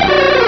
pokeemerald / sound / direct_sound_samples / cries / shuckle.aif